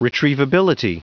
Prononciation du mot retrievability en anglais (fichier audio)
Prononciation du mot : retrievability